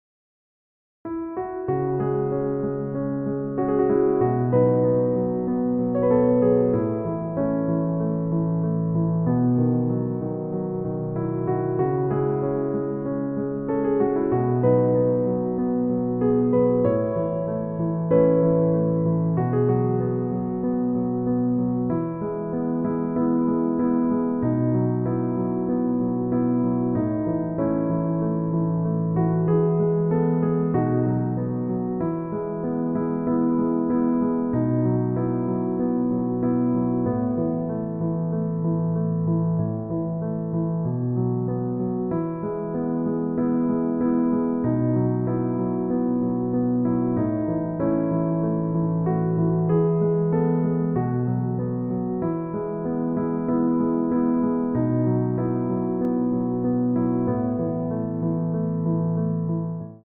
MEDIUM Piano Tutorial